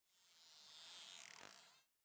Minecraft Version Minecraft Version 25w18a Latest Release | Latest Snapshot 25w18a / assets / minecraft / sounds / mob / dolphin / idle_water5.ogg Compare With Compare With Latest Release | Latest Snapshot
idle_water5.ogg